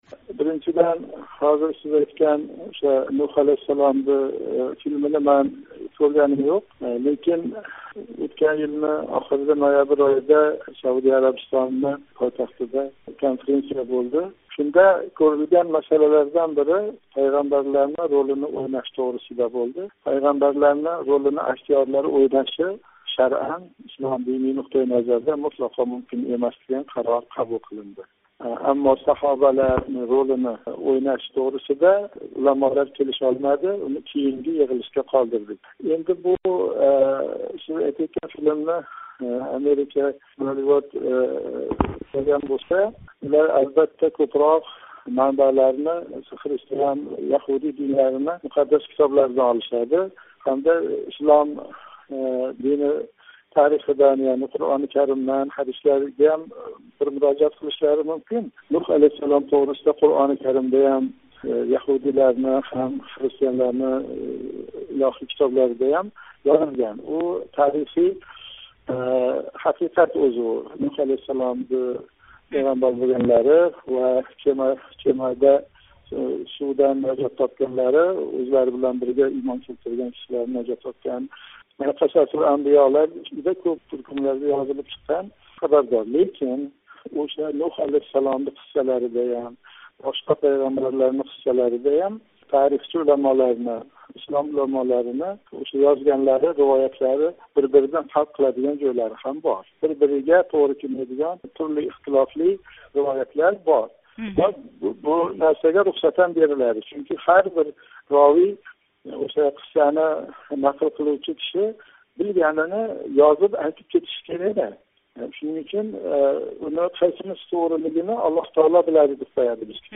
суҳбат